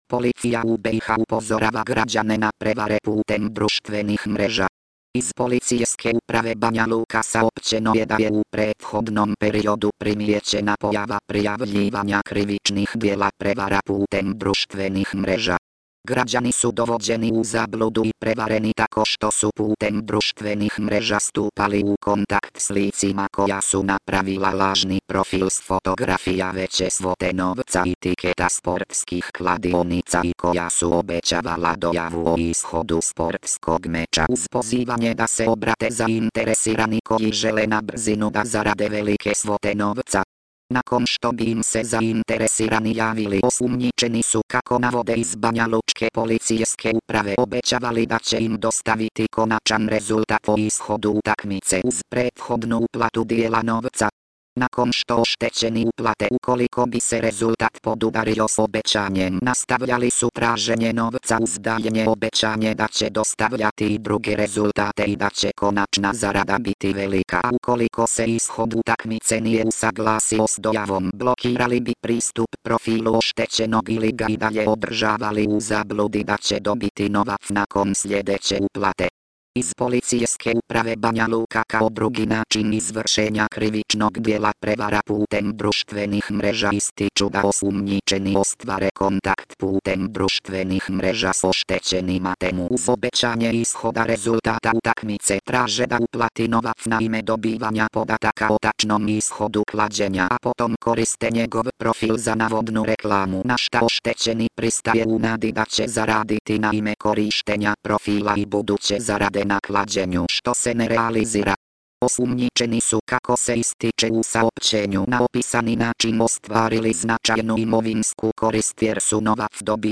prilog od radia mp3